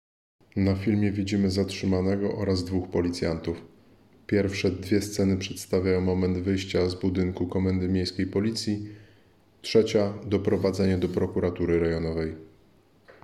Nagranie audio Audiodeskrypcja do filmu.m4a